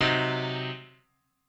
piano7_39.ogg